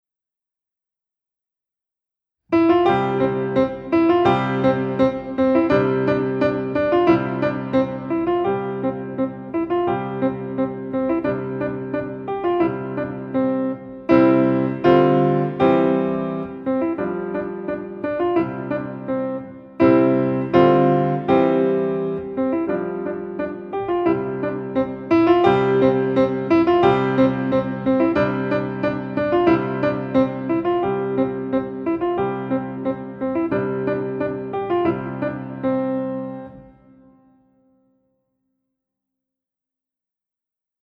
Gattung: Klavier
Besetzung: Instrumentalnoten für Klavier
stilistisch eher traditionell und volksliedhaft